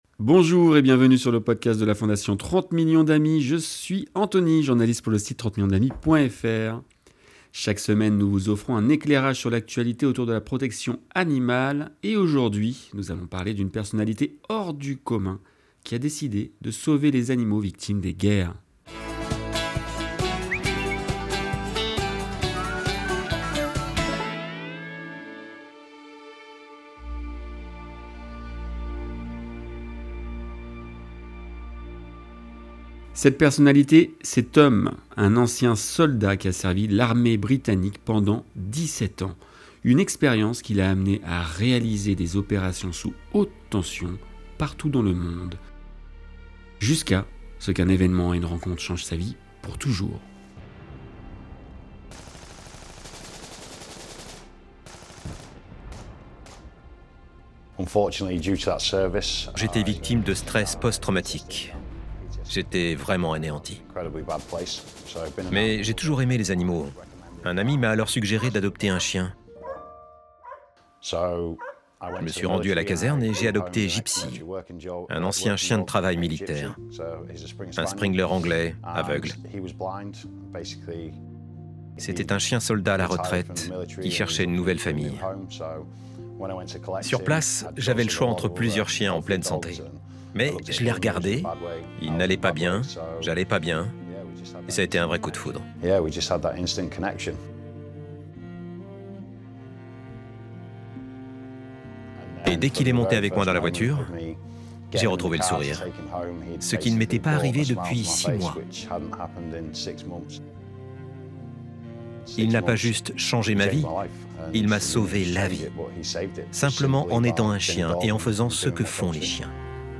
INTERVIEW_3_Breaking_the_Chains.mp3